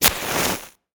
环境音 / 非循环音(SE)
0026_点雪茄.ogg